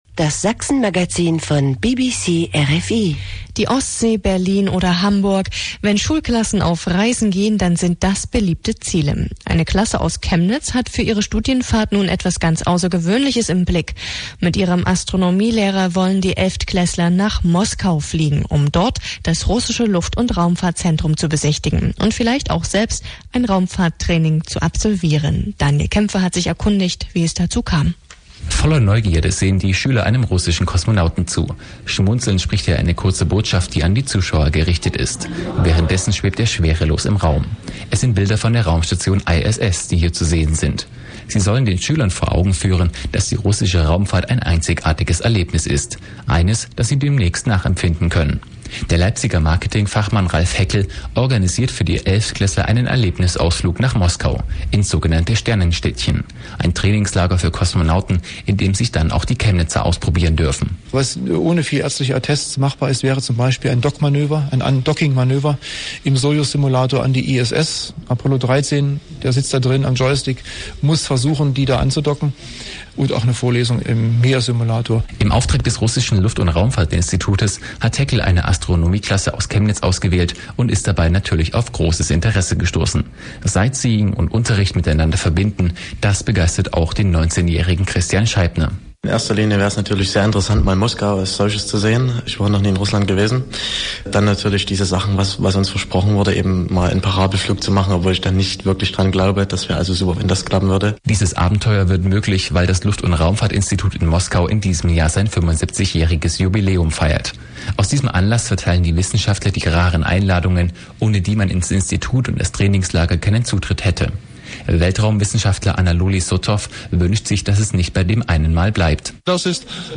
Deutsch BBC - begleitet die erste Vortragsveranstaltung (BBC - reports about the 1st presentaion)